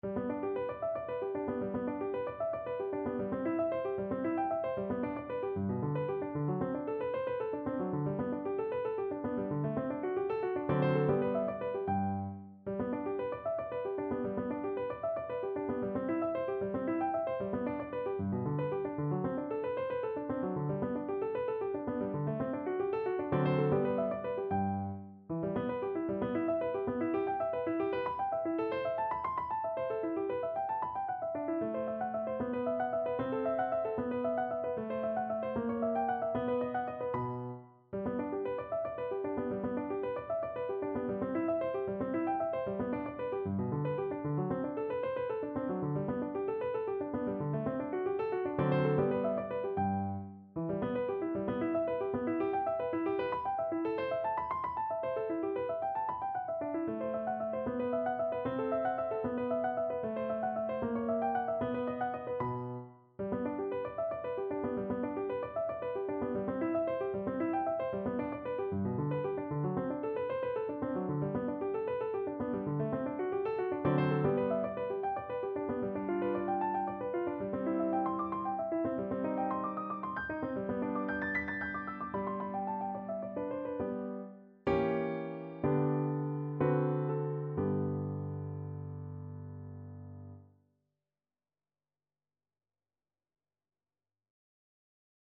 No parts available for this pieces as it is for solo piano.
G major (Sounding Pitch) (View more G major Music for Piano )
4/4 (View more 4/4 Music)
Allegro moderato (=152) (View more music marked Allegro)
Piano  (View more Intermediate Piano Music)
Classical (View more Classical Piano Music)